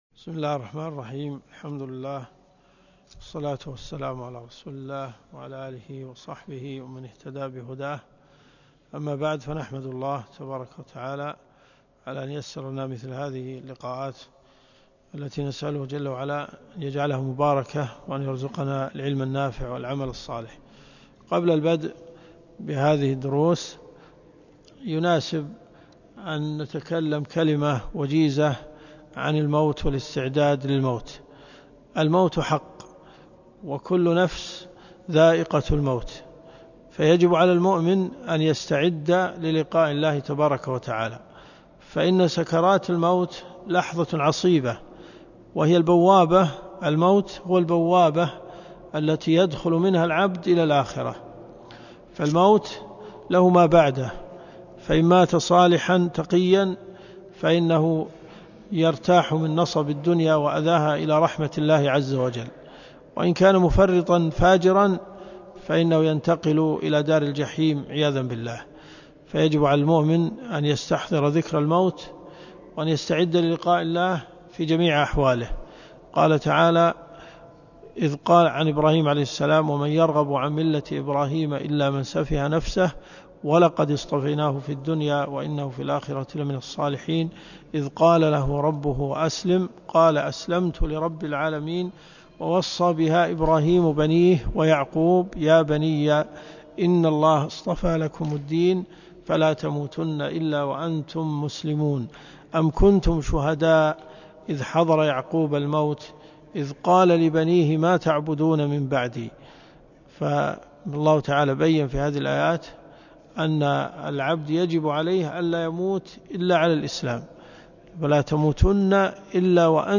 الدروس الشرعية
جامع الملك خالد